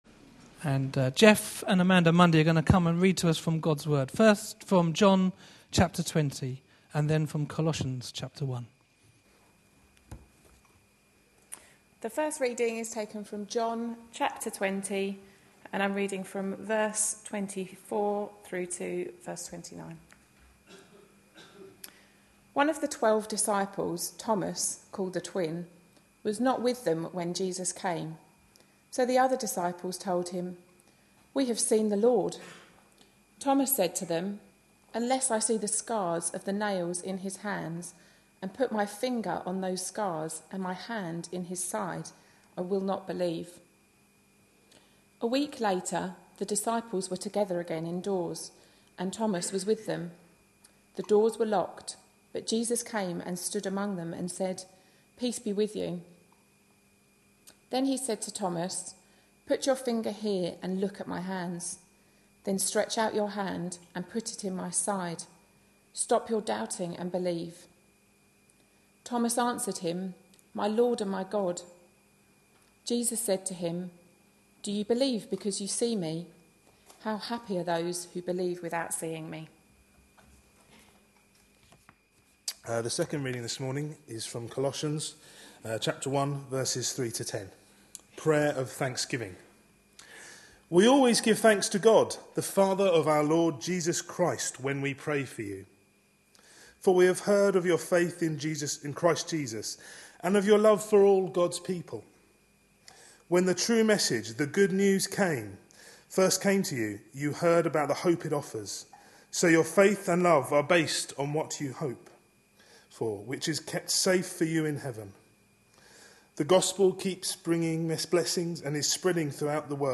A sermon preached on 28th April, 2013, as part of our Three things the risen Jesus said: series.